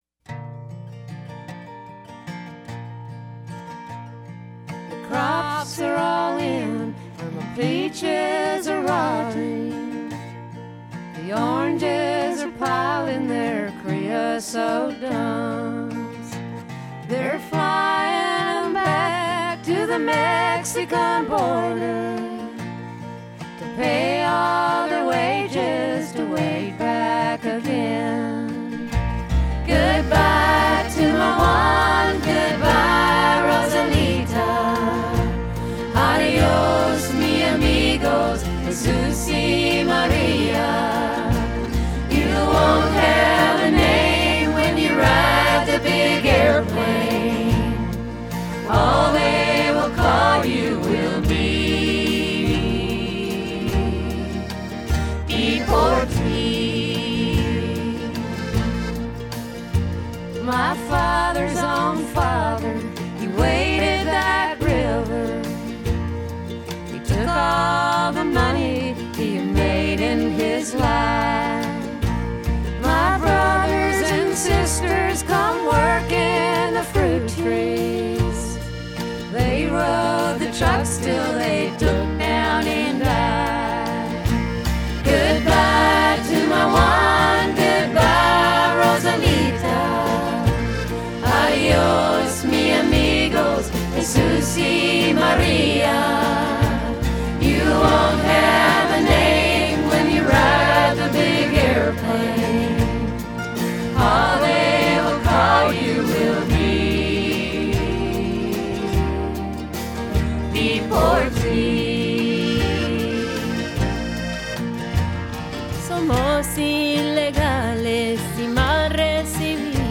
Il country diventa folk, malinconico, ma gentile.